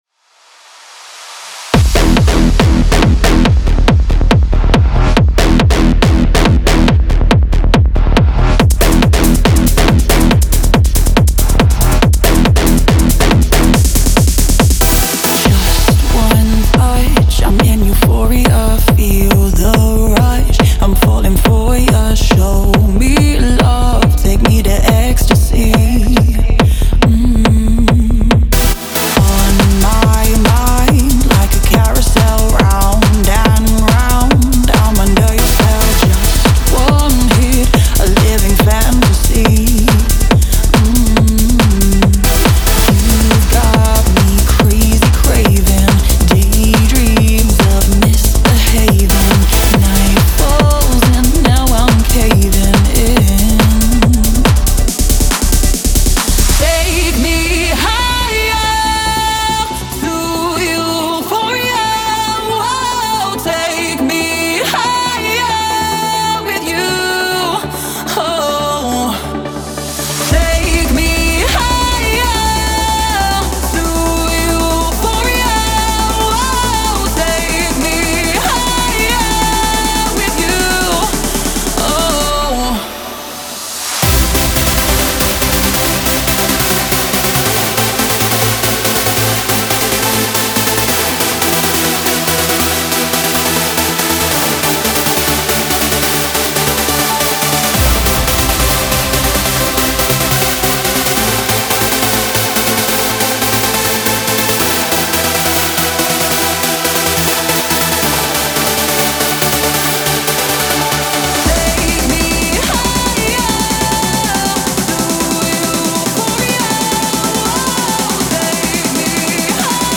• Жанр: Trance